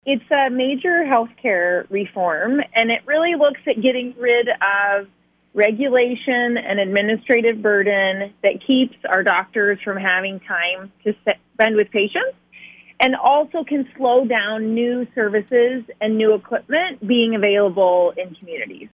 Kara Warme, Iowa State Senator in District 26, joined the KFJB line on Friday to discuss HF2635 working its way through the Iowa Legislature.